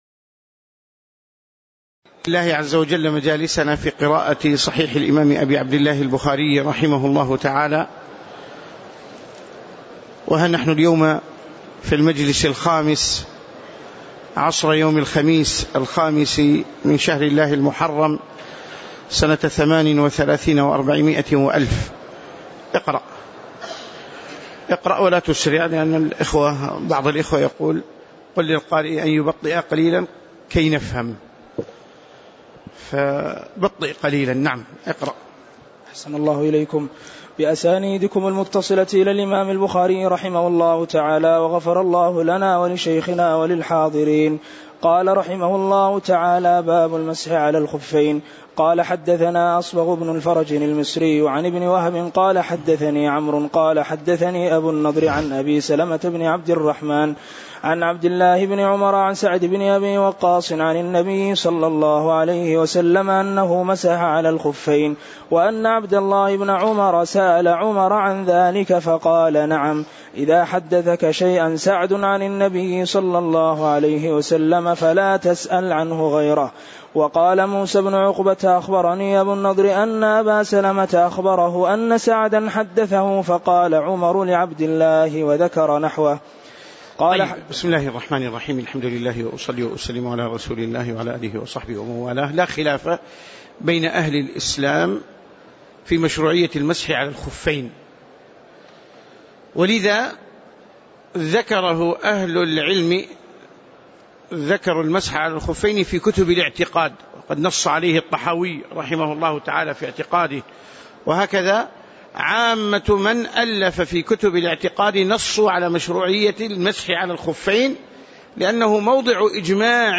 تاريخ النشر ٥ محرم ١٤٣٨ هـ المكان: المسجد النبوي الشيخ